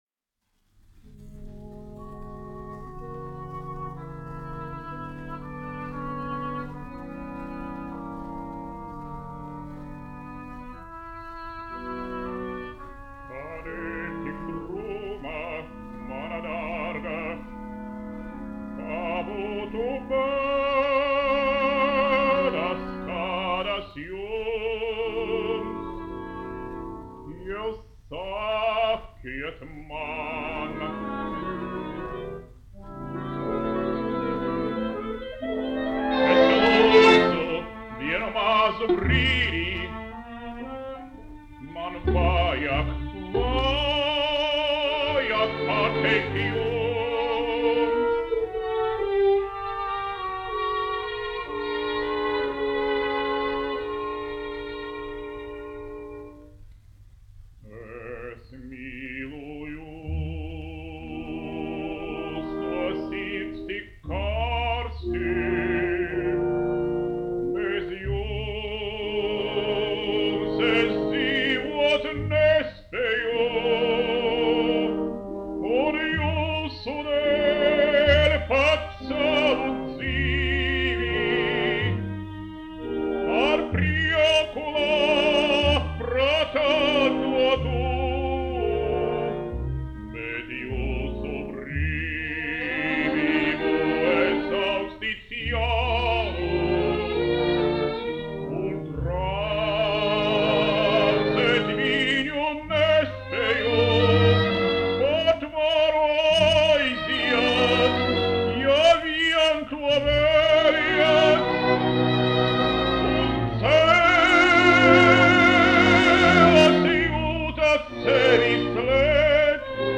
1 skpl. : analogs, 78 apgr/min, mono ; 25 cm
Operas--Fragmenti
Skaņuplate